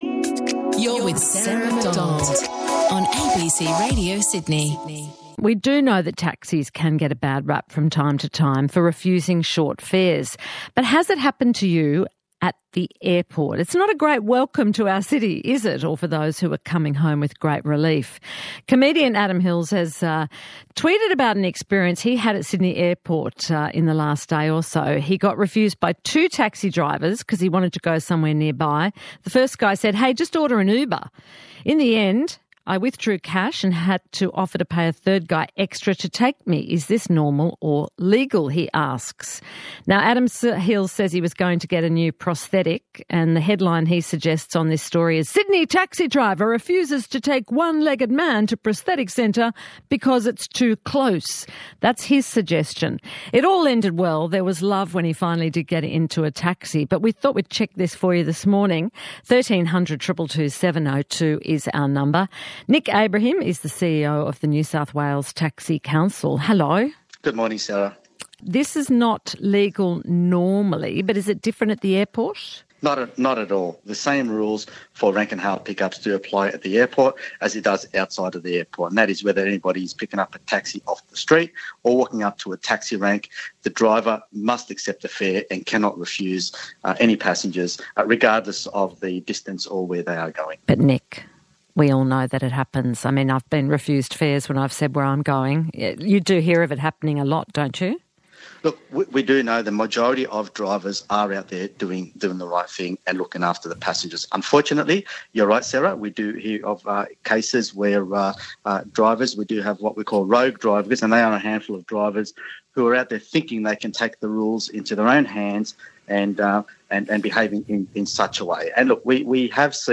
Click here to listen to the radio interview